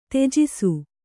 ♪ tege